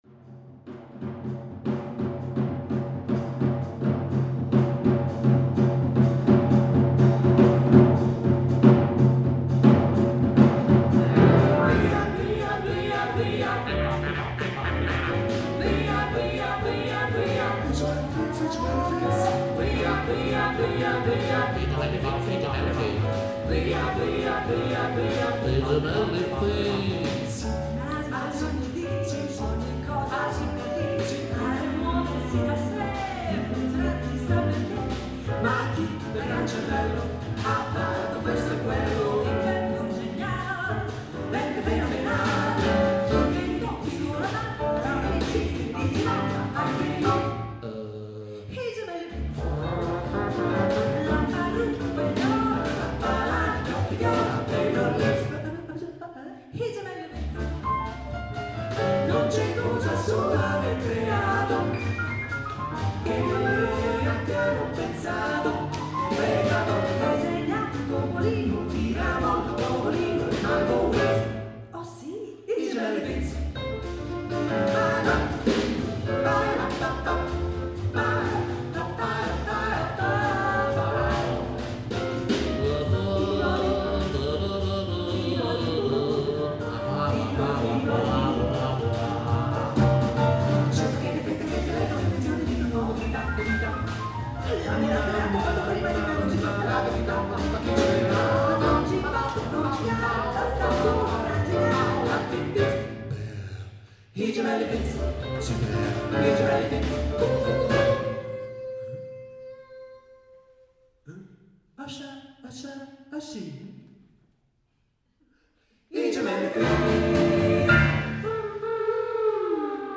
tre cantanti/attori più tre musicisti
piano
Drums
Bass